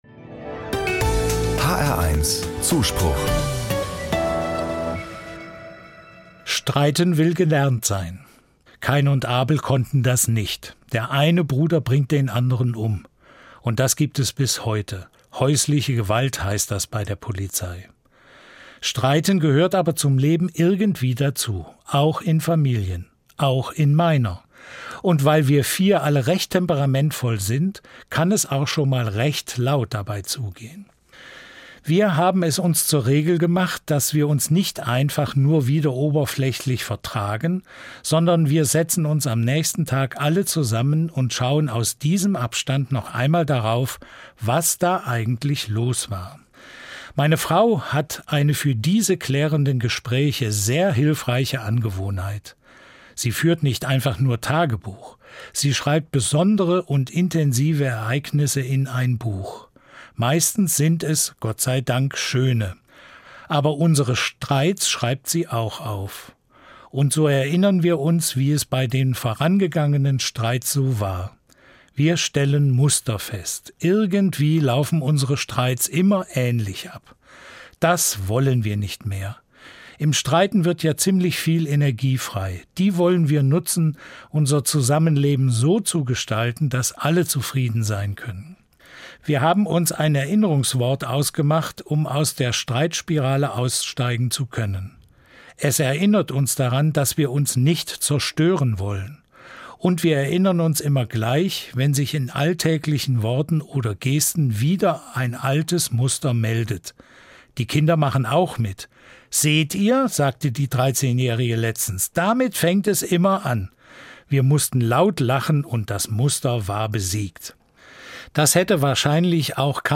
Evangelischer Pfarrer i. R., Kassel